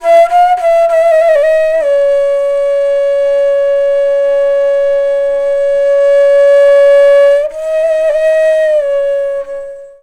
FLUTE-B12 -R.wav